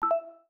Message Ping X2 2.wav